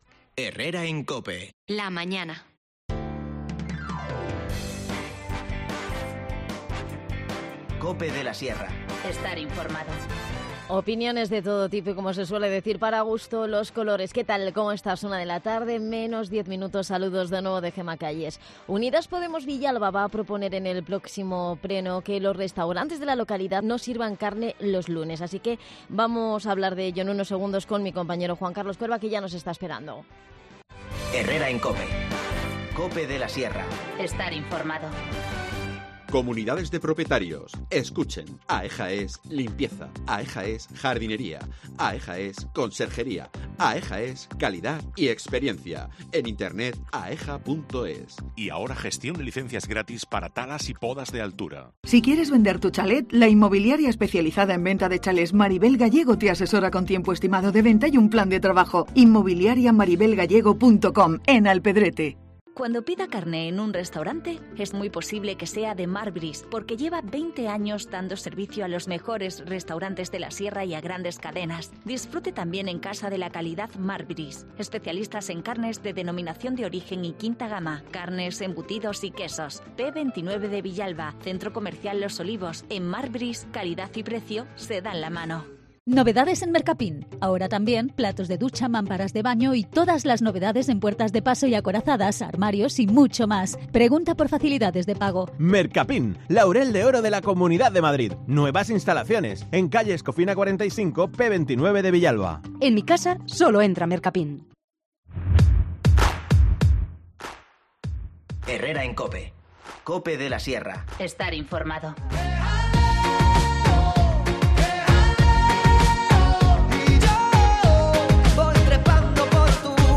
con mucha ironía